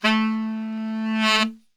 A 1 SAXSWL.wav